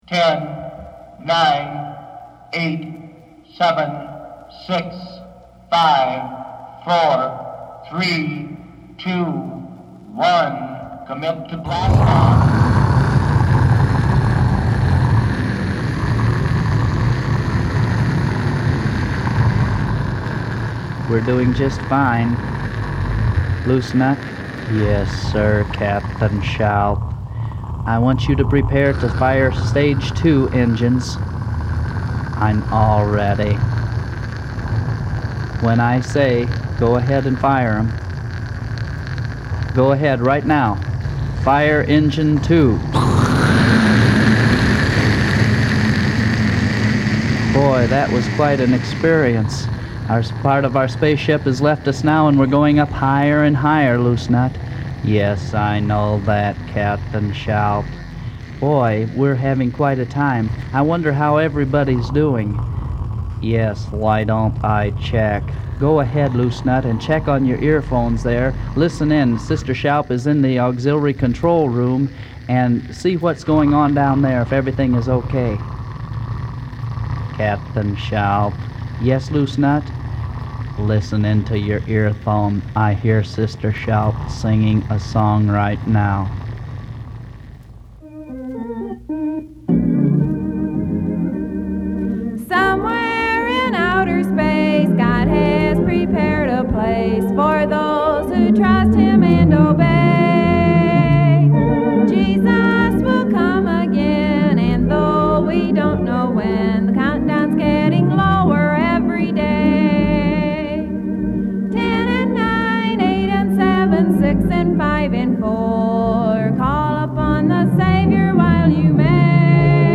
"Prepare to Fire"! A space-age Rapture song (mp3 link)